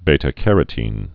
(bātə-kărə-tēn, bē-)